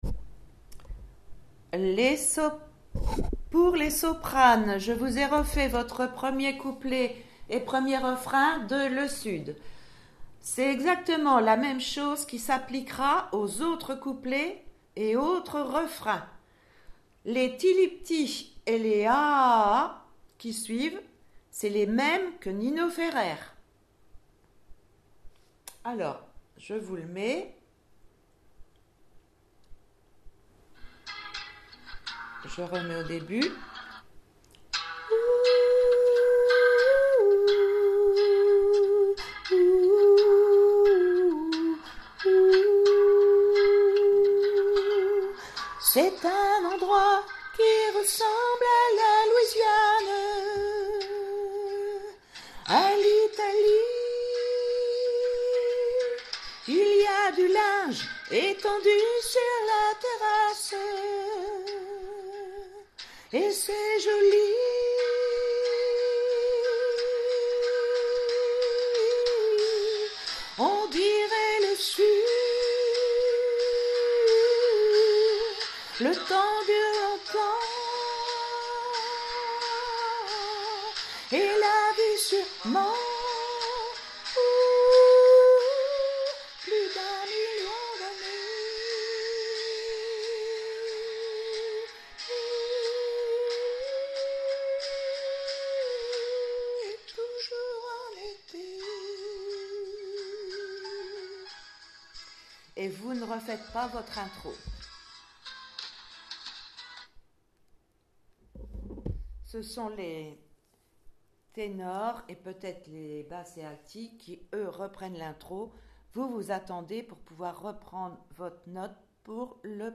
Le sud soprani 2